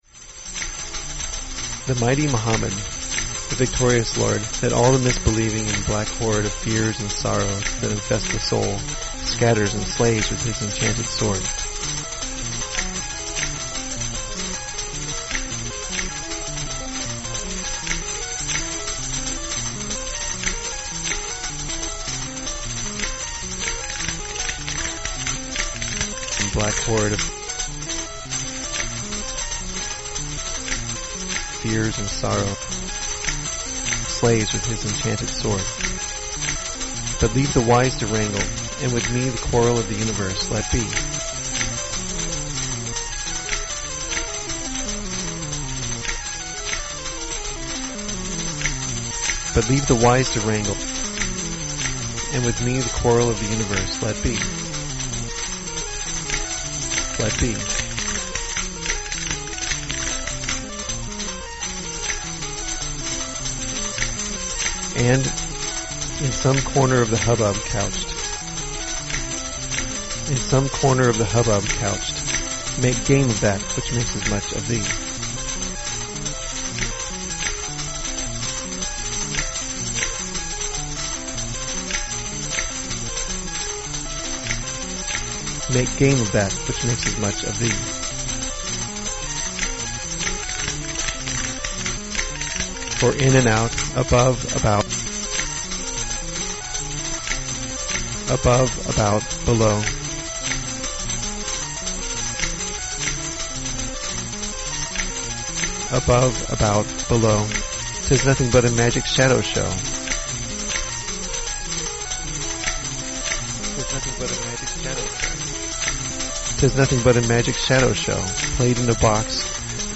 Creator's Tags: poetry electronic arpeggio sound collage
Description: Recital of the Rubaiyat of Omar Khayyam.